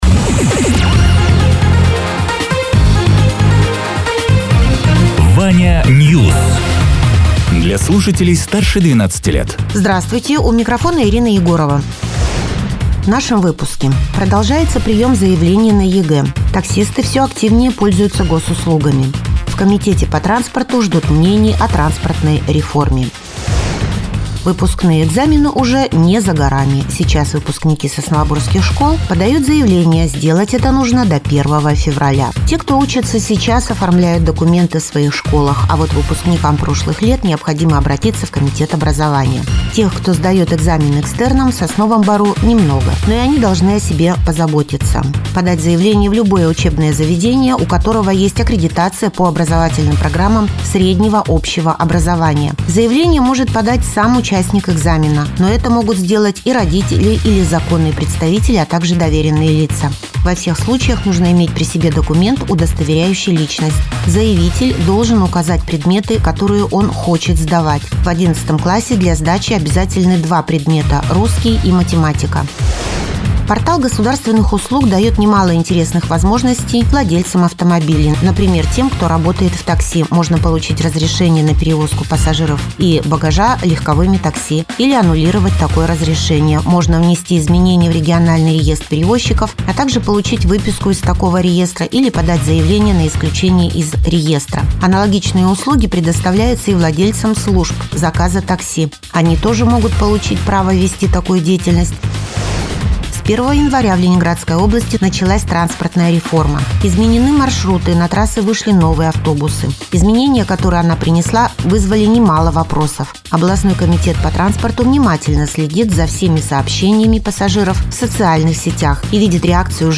Радио ТЕРА 22.01.2025_08.00_Новости_Соснового_Бора